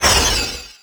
Magic_Disappear.wav